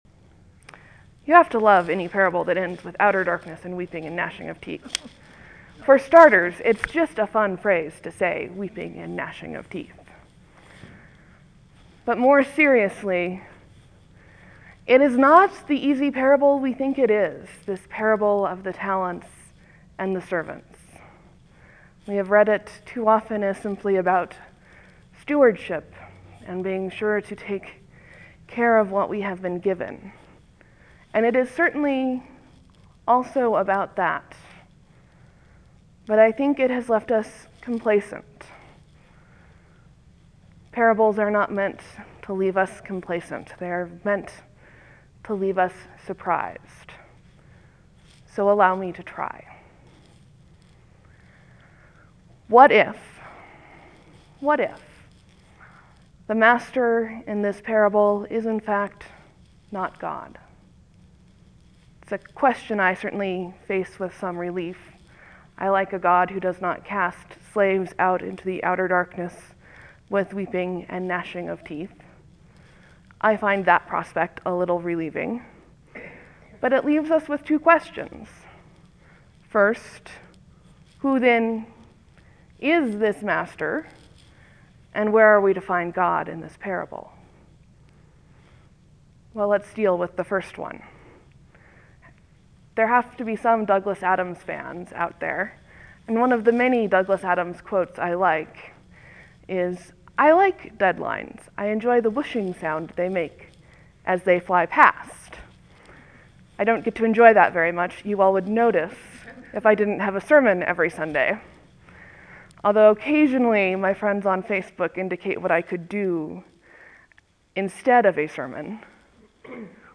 Sermon, Leave a comment
(There will be a few moments of silence before the sermon starts.  Thank you for your patience.)